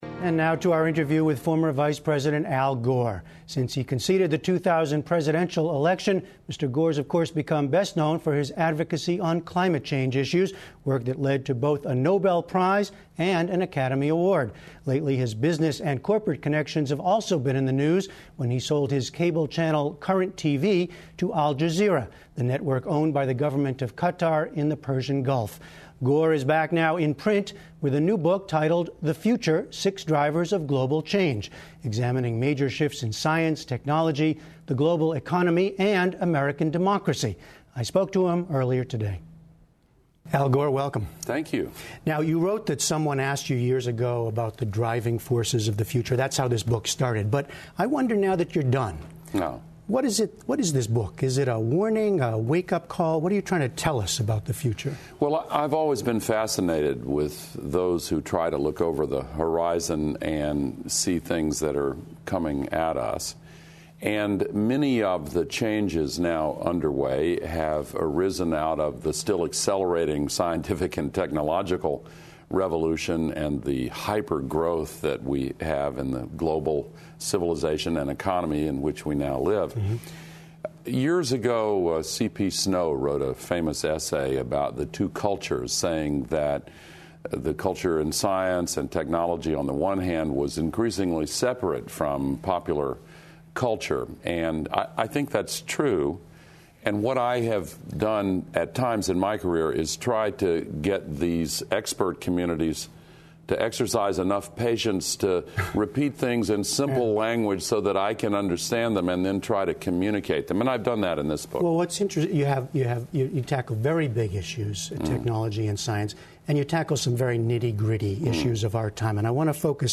JEFFREY BROWN:And now to our interview with former Vice President Al Gore.